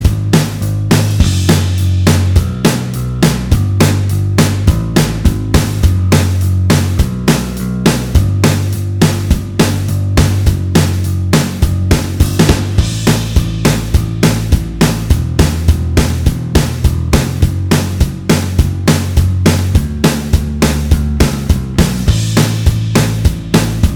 Minus All Guitars Pop (1970s) 3:33 Buy £1.50